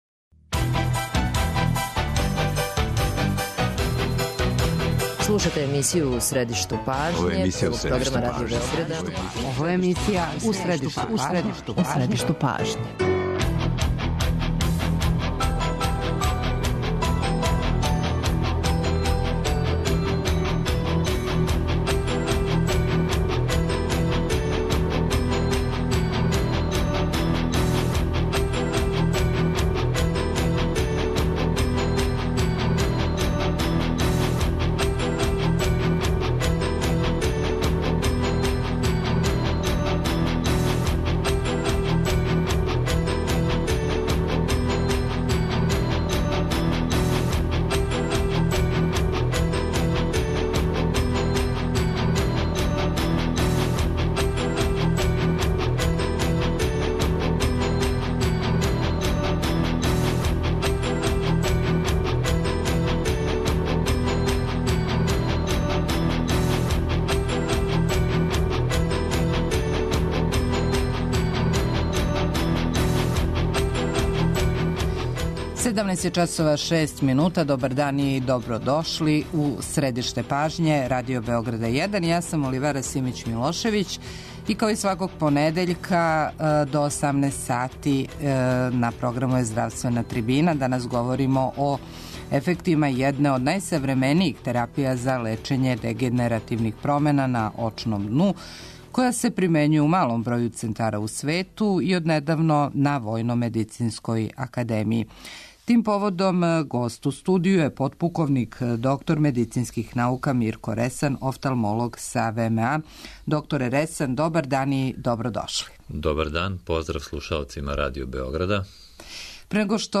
У здравственој трибини о ефектима једне од најсавременијих терапија за лечење дегенеративних промена на очном дну, која се примењује у само шест европских центара, а од недавно и на Очној клиници ВМА.